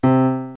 SAMPLES : Piano
piano nē 2
piano2.mp3